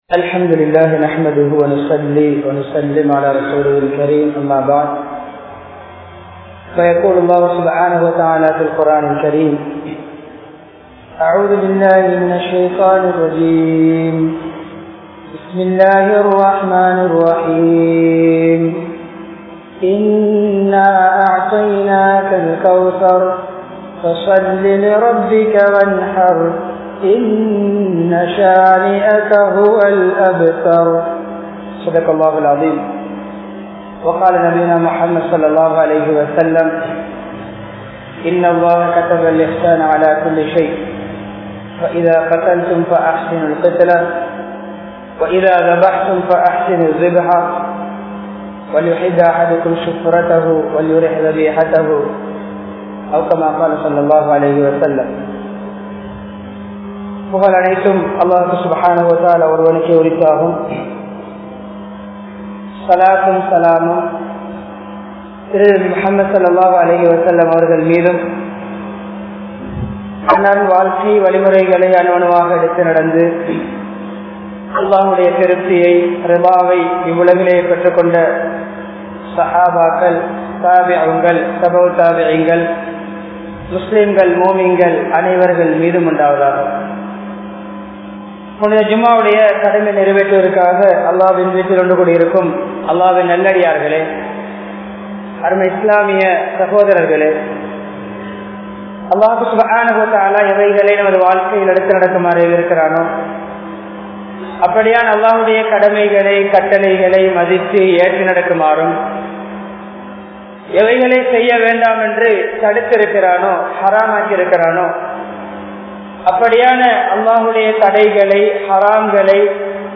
Hajjum Kurbanum (ஹஜ்ஜூம் குர்பானும்) | Audio Bayans | All Ceylon Muslim Youth Community | Addalaichenai
Jumua Masjidh